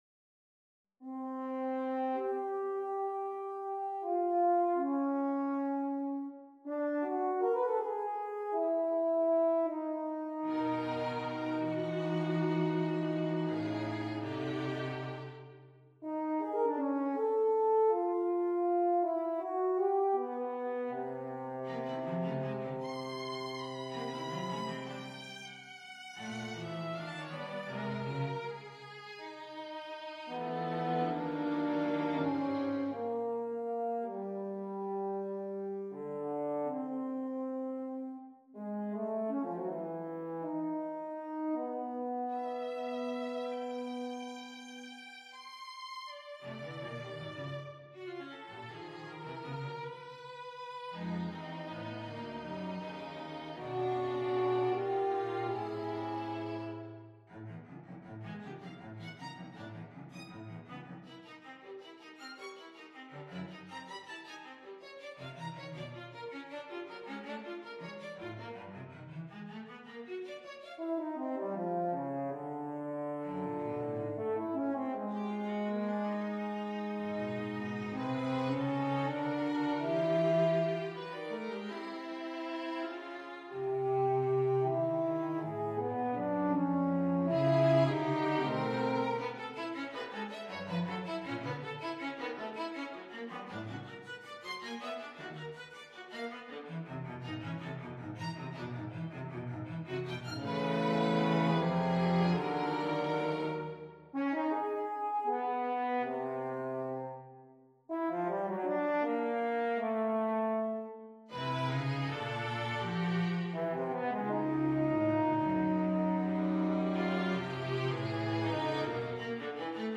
on a purpose-selected tone row
Allegro - Poco più mosso - Molto meno mosso
For anyone possibly weary of this seemingly endless series of little chamber pieces inspired by Vedantic Upanishads, the good news is that only one more such composition is in prospect: Maitrayani, the 13th and final Principal Upanishad in the most expansive Hindu tradition.